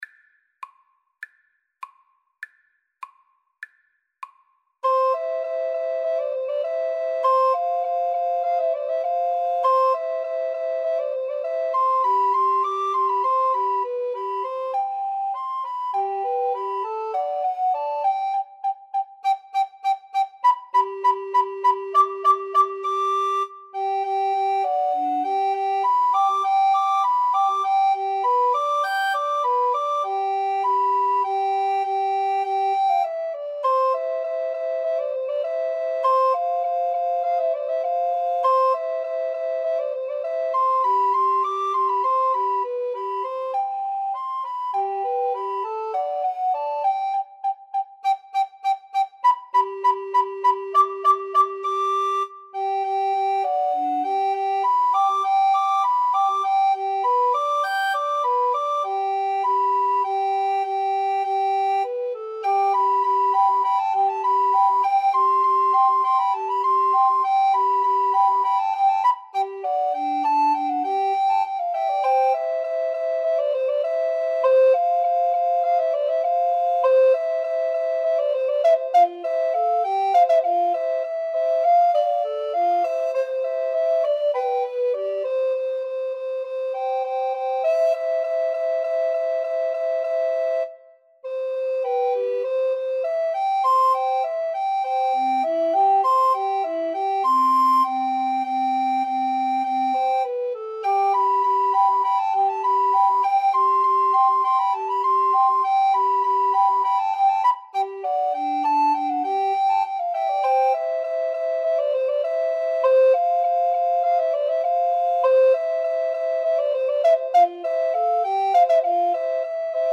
~ = 100 Allegretto
C major (Sounding Pitch) (View more C major Music for Recorder Trio )
Classical (View more Classical Recorder Trio Music)